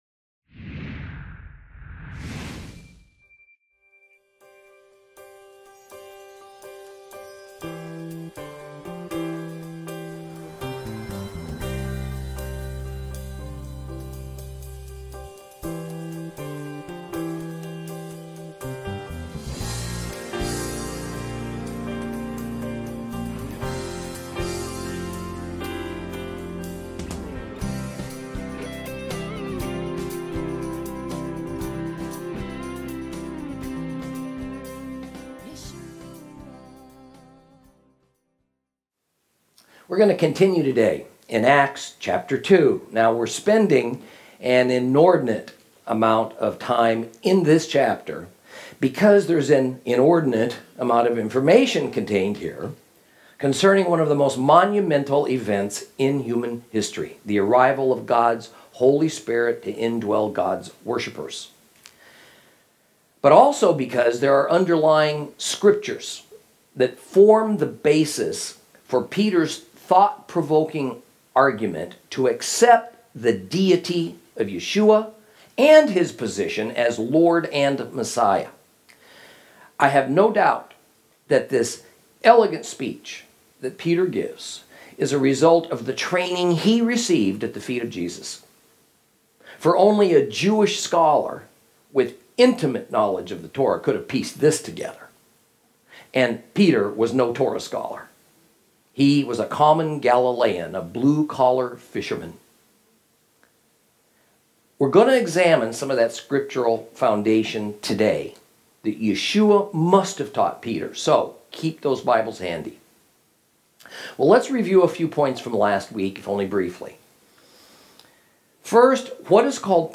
Lesson 6 Ch2 - Torah Class